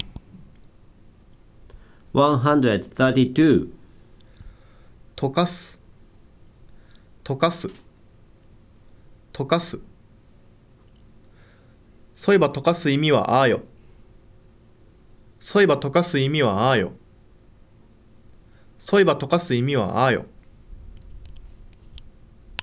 If you click the word in a celll in the table, then you can hear the `non-past' form of the verb and a sentence containing the `non-past' form as the verb of the adnominal clause in Saga western dialect.